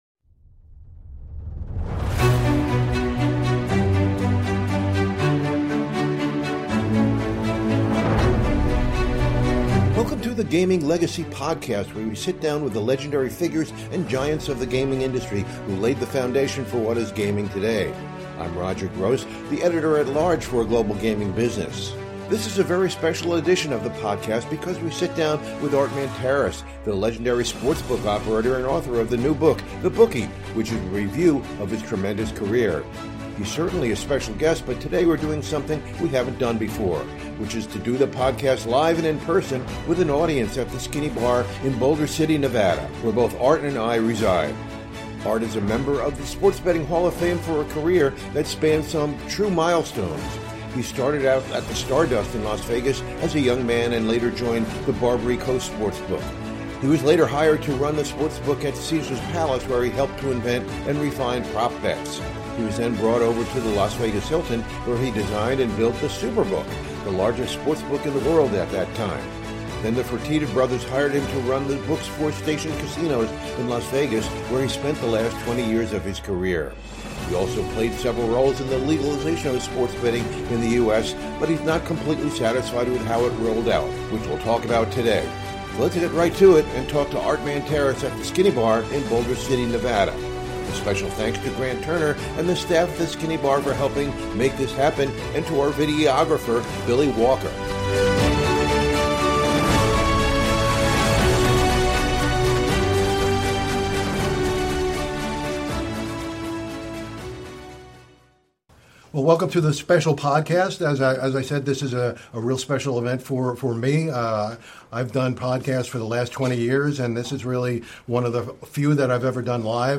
He joins the podcast for something we haven’t done before, which is present the podcast live and in person with an audience at the Skinny Bar in Boulder City, Nevada.